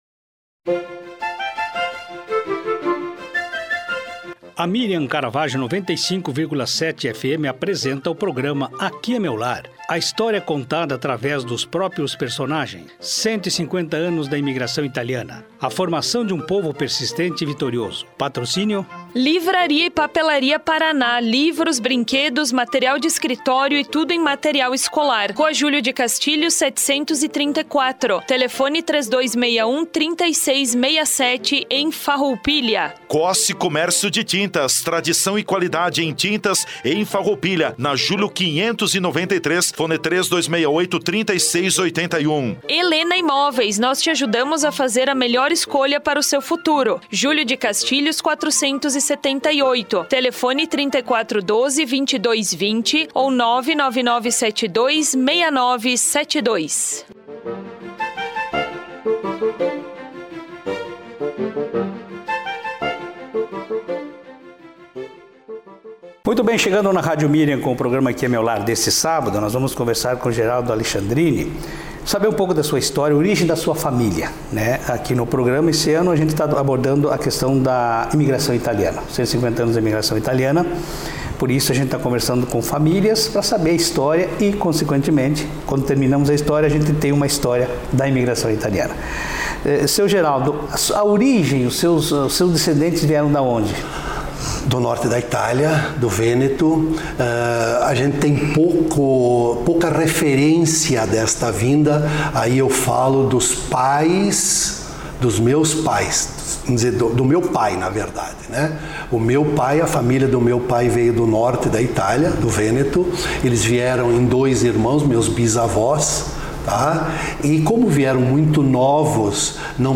A reportagem faz parte do projeto Aqui é Meu Lar, que em 2025 tratou dos 150 anos da Imigração Italiana, ouvindo histórias das famílias de imigrantes italianos.